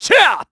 Clause_ice-Vox_Attack2_kr.wav